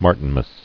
[Mar·tin·mas]